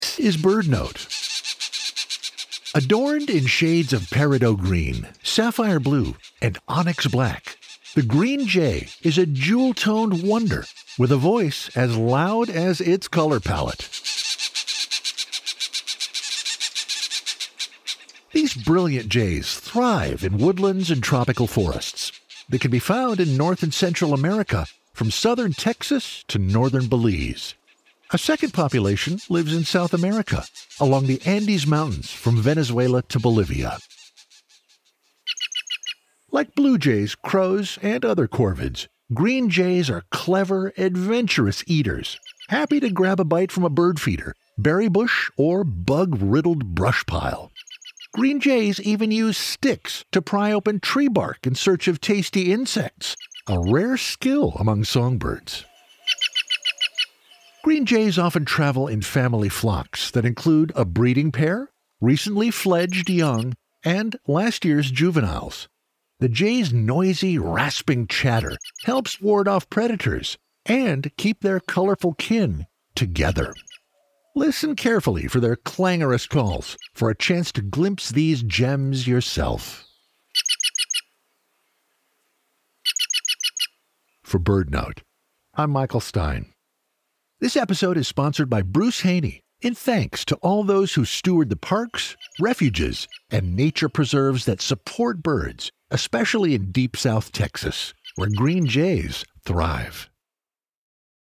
Adorned in shades of peridot green, sapphire blue, and onyx black, the Green Jay is a jewel-toned wonder with a voice as loud as its color palette. Their range is split over two regions, one from southern Texas to northern Belize and a second along the Andes Mountains from Venezuela to Bolivia.